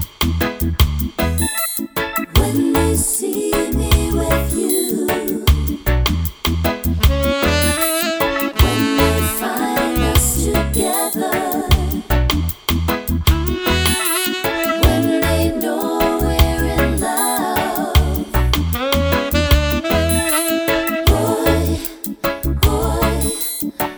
no Backing Vocals Reggae 4:03 Buy £1.50